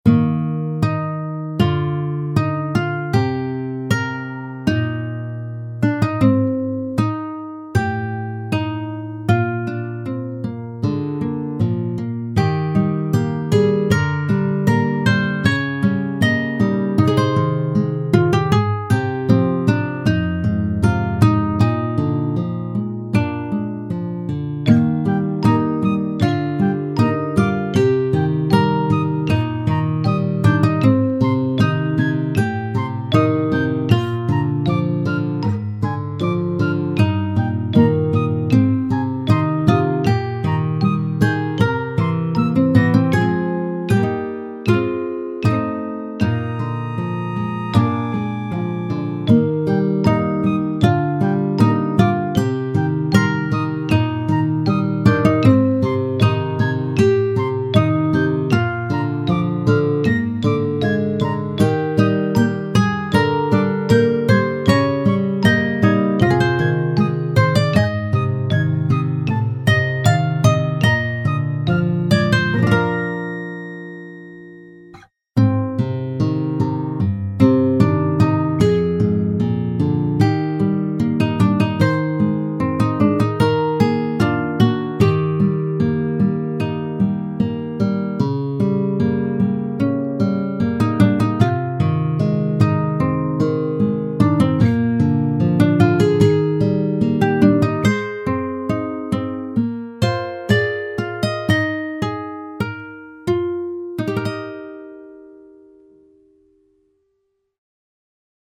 ogg(R) ギター協奏曲 アレンジ
しっとりゆるやかなギターアレンジ。